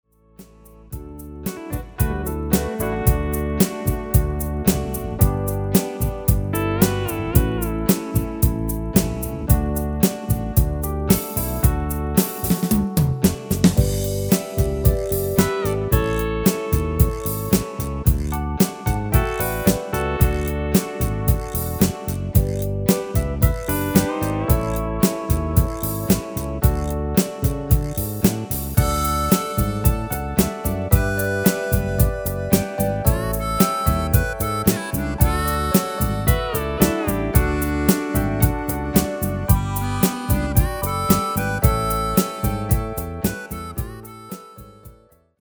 Demo/Koop midifile
Genre: Country & Western
Toonsoort: D
- Vocal harmony tracks
Demo's zijn eigen opnames van onze digitale arrangementen.